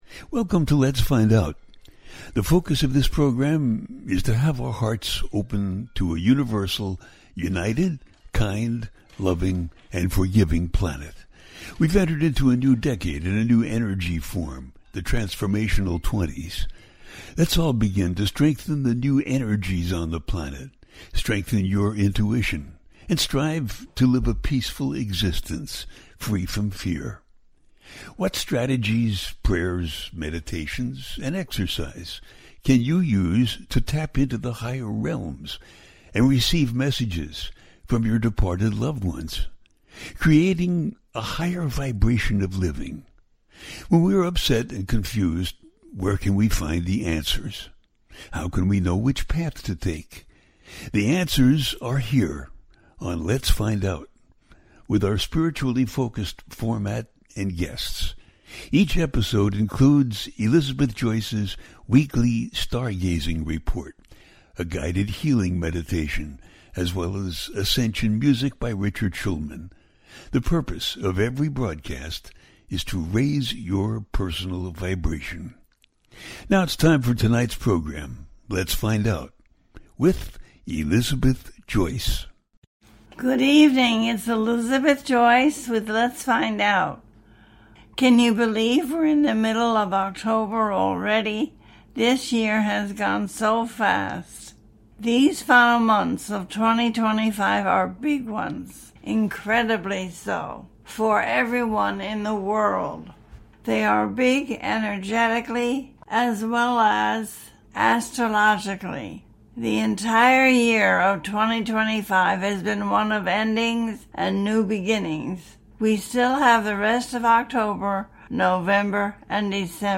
The Libra New Moon - Your Choices - A teaching show
The listener can call in to ask a question on the air.
Each show ends with a guided meditation.